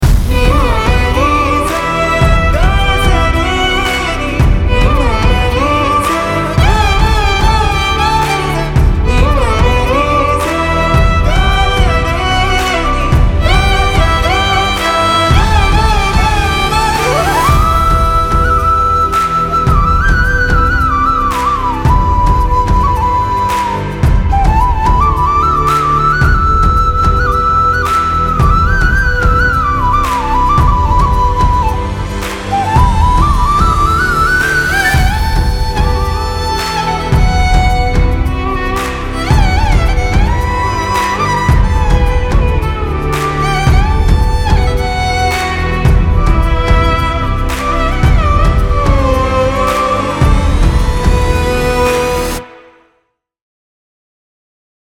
in high quality without disturbances